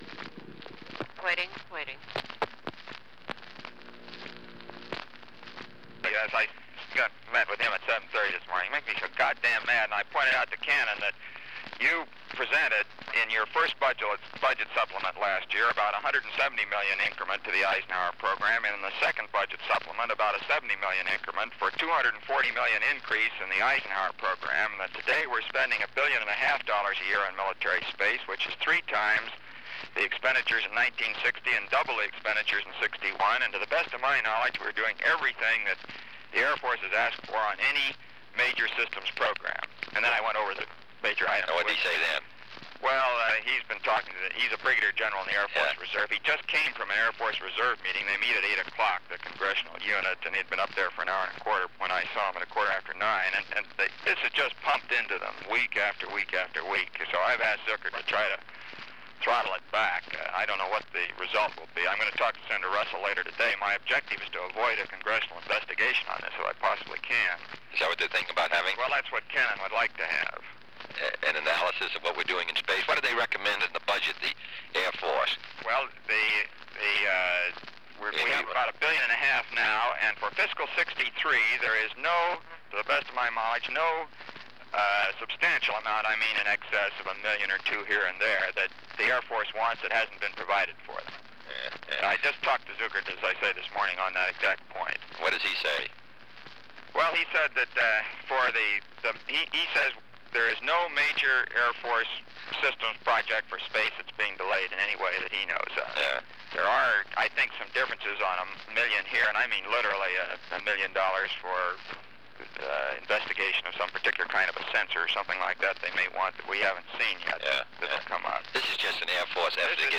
Conversation with Robert McNamara
Secret White House Tapes | John F. Kennedy Presidency Conversation with Robert McNamara Rewind 10 seconds Play/Pause Fast-forward 10 seconds 0:00 Download audio Previous Meetings: Tape 121/A57.